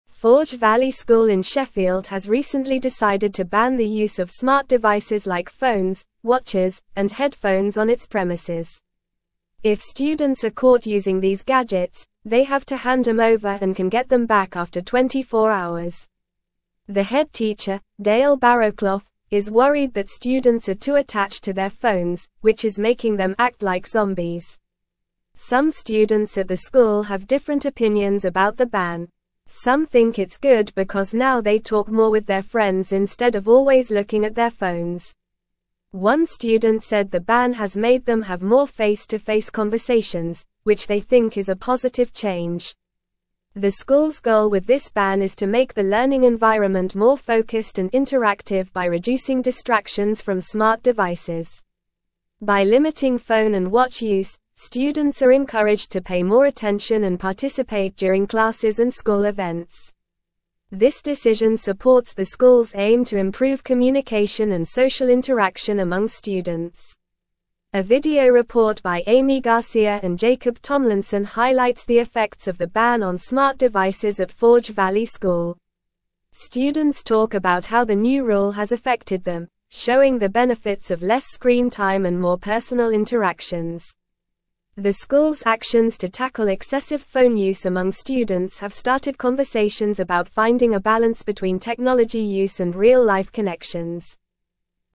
Normal
English-Newsroom-Upper-Intermediate-NORMAL-Reading-Forge-Valley-School-Implements-Ban-on-Smart-Devices.mp3